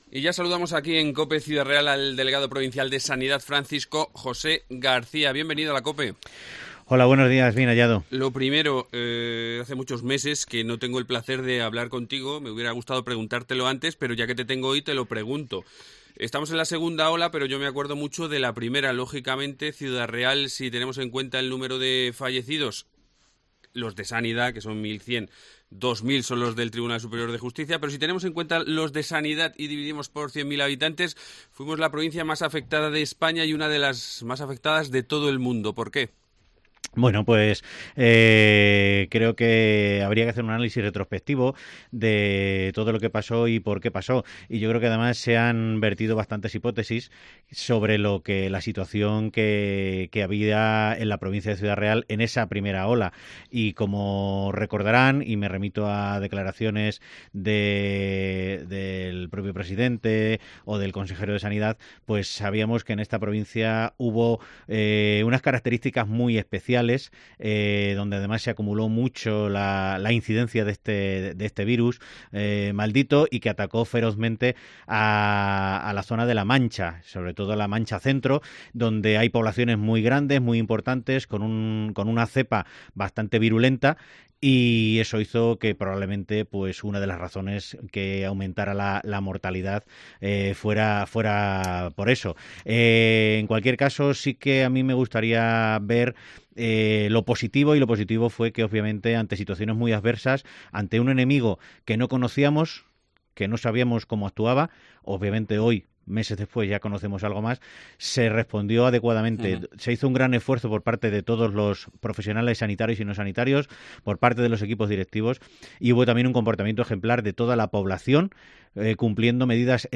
Entrevista al delegado de Sanidad